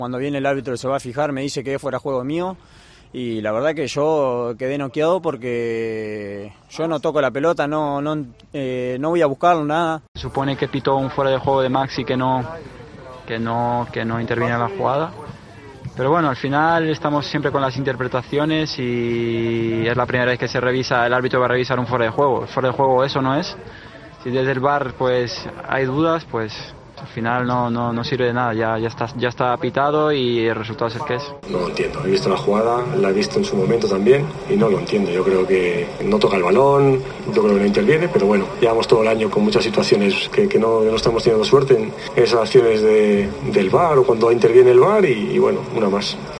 AUDIO. Maxi. Rodrigo y Celades opinan sobre la polémica